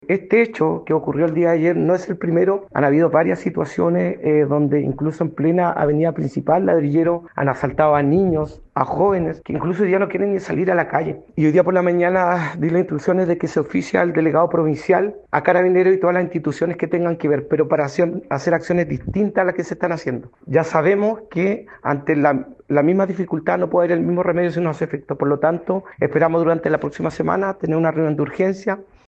Ante ello, el alcalde suplente de Quellón, Pedro Barría, dijo que existe preocupación en la comuna porque no sería el primer hecho de estas características que ocurre en las últimas semanas.